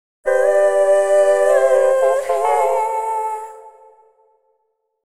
各エフェクトの Mix と、Wet音だけを抽出したものです。
ボーカル・ホール系のプリセットを、ほぼそのまま AUX に置き、リターン量が同じになるように設定。
音源のせいか、あまり 良い / 悪い の違いは出てないような気がします。